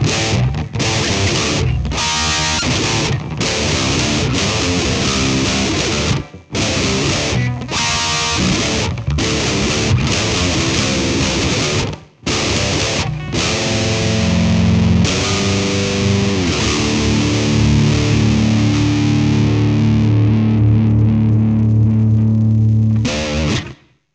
Rocktron Piranha and Yamaha power amp plus a Marshall 1960B JCM800 cab.
Audio Here’s a sound sample of the Rocktron Piranha.